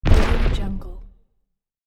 دانلود افکت صدای انفجار بازی
افکت صدای انفجار بازی یک گزینه عالی برای هر پروژه ای است که به صداهای بازی و جنبه های دیگر مانند انفجار، منفجر شدن نیاز دارد.
Sample rate 16-Bit Stereo, 44.1 kHz
Looped No